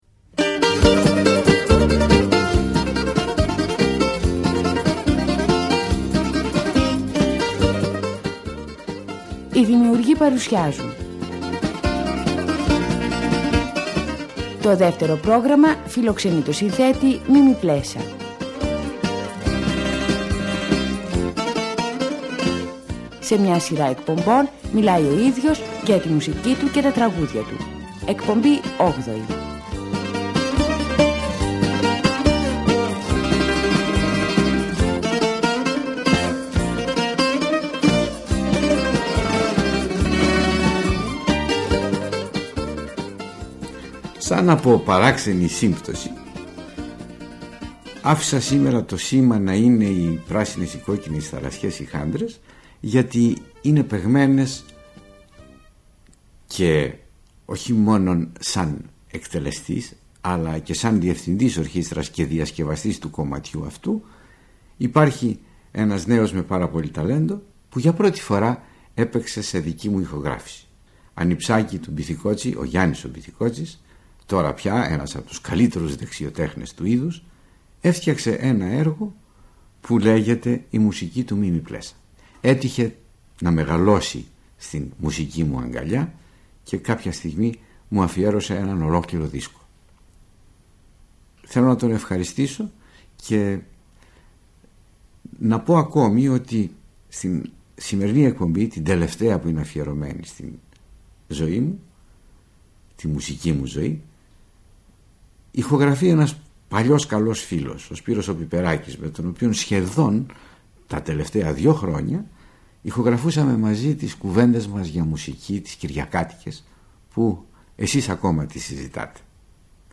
Στις εκπομπές αυτές, ο μεγάλος συνθέτης αυτοβιογραφείται, χωρίζοντας την έως τότε πορεία του στη μουσική, σε είδη και περιόδους, διανθίζοντας τις αφηγήσεις του με γνωστά τραγούδια, αλλά και με σπάνια ηχητικά ντοκουμέντα.